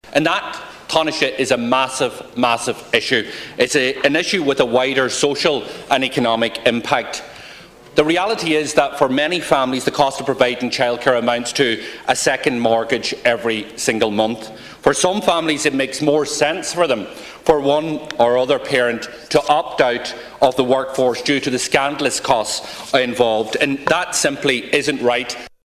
But Sinn Féin’s Finance Spokesman Donegal Deputy Pearse Doherty says more needs to be done to reduce the cost for families: